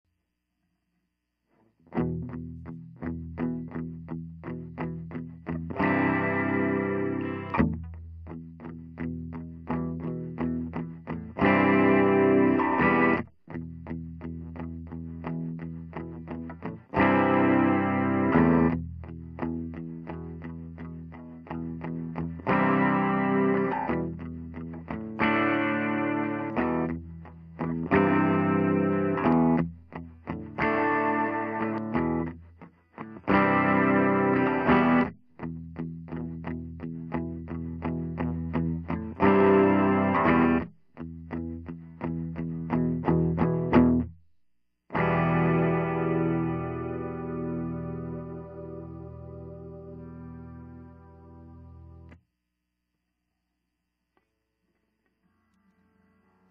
The reverb also cuts off abruptly when you let go the switch. so you can have single note or phrase drenched on reverb and the rest of the riff or melody dry.
Remember when we were into those nice big long sad chords, but with a twist,
It also has a nice western landscape feel … you yourself can choose your era !
new-wave-chords.m4a